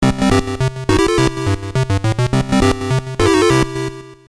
PSG音源の音の例1